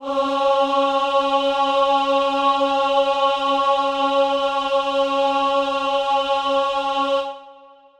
Choir Piano (Wav)
C#4.wav